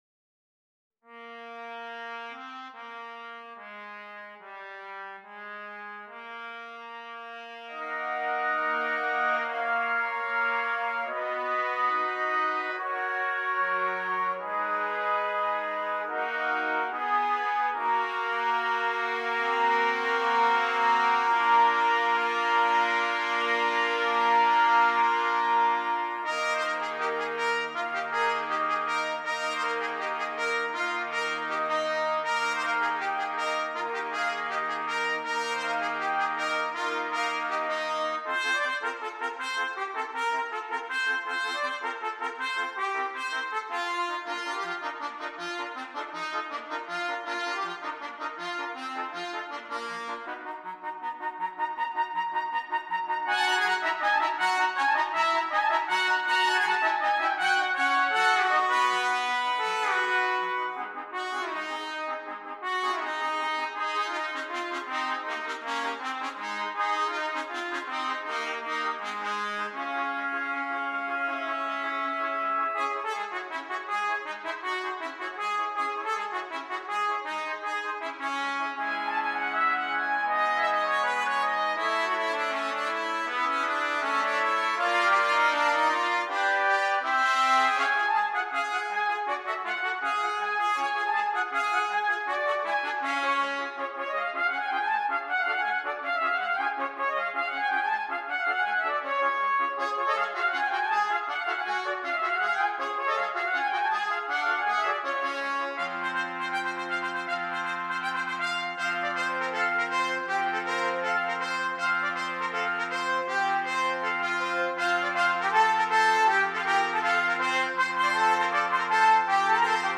6 Trumpets